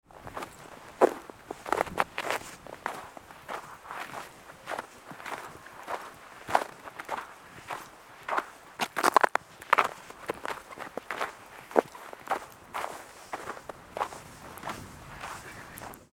Walking-on-dry-crunchy-snow-sound-effect.mp3